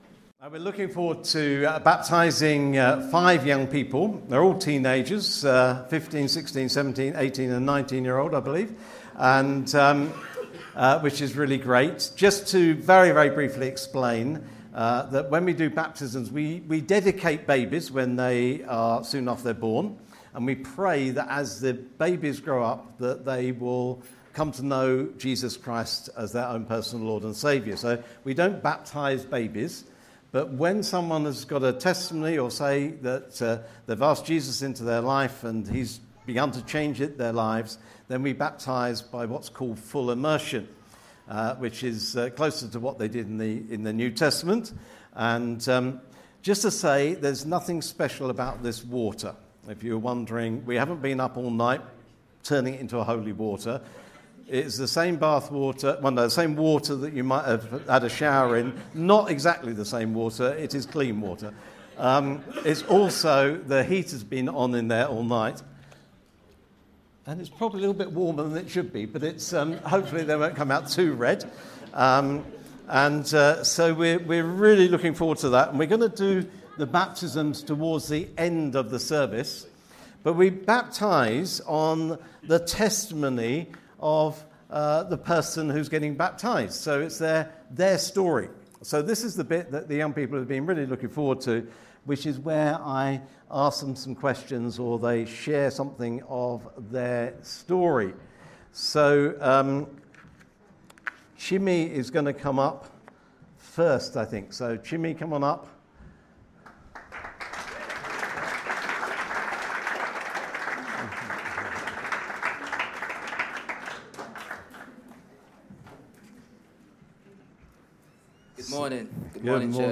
Baptism service testimonies